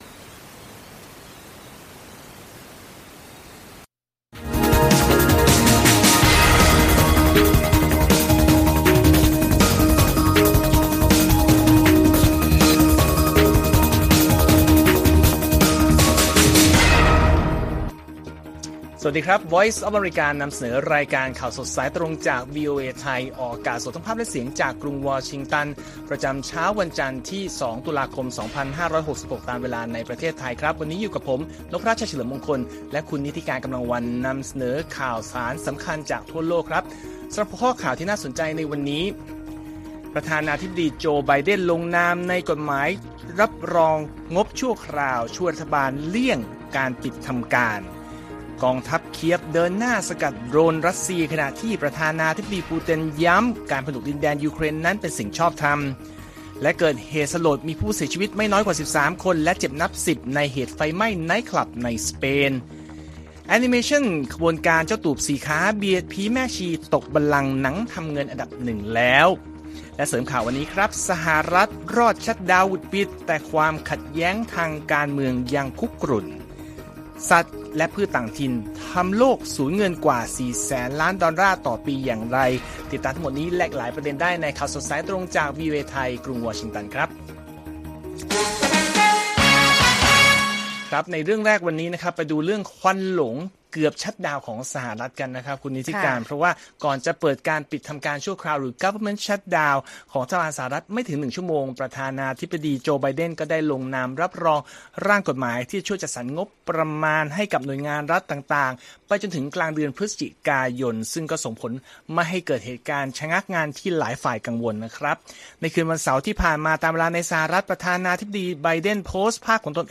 ข่าวสดสายตรงจากวีโอเอไทย วันจันทร์ ที่ 2 ต.ค. 2566